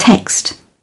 11.text /tekst/ (v): nhắn tin